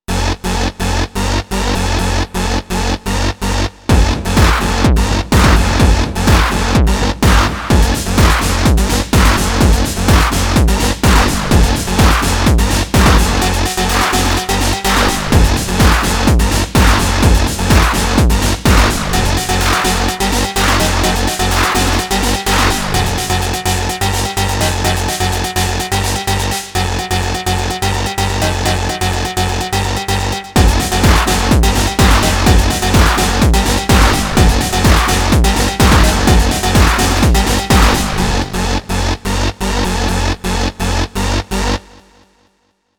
Sound Design : Techno stab
… made up Stabs on the Syntakt …